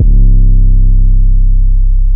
YM 808 9.wav